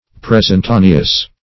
Search Result for " presentaneous" : The Collaborative International Dictionary of English v.0.48: Presentaneous \Pres`en*ta"ne*ous\, a. [L. praesentaneus.